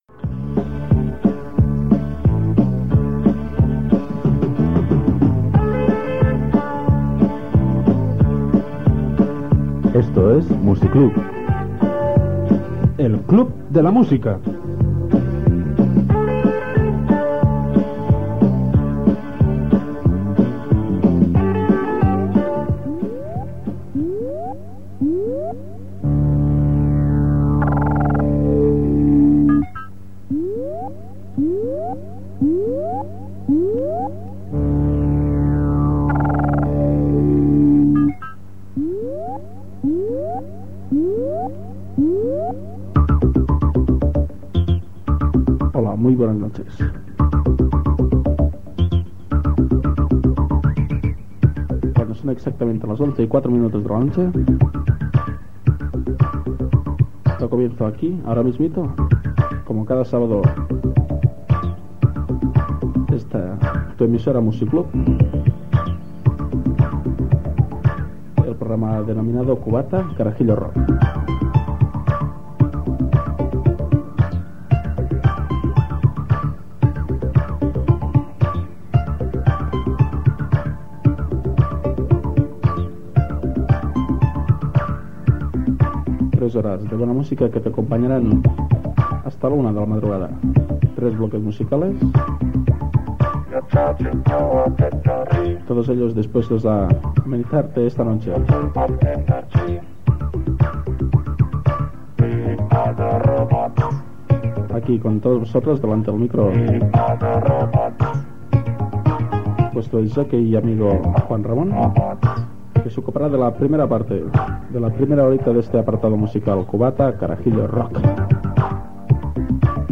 Inici del programa Gènere radiofònic Musical